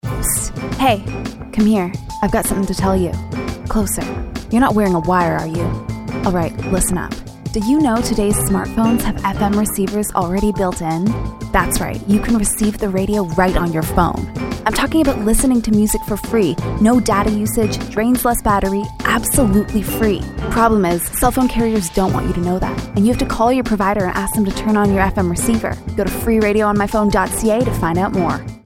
Type: PSA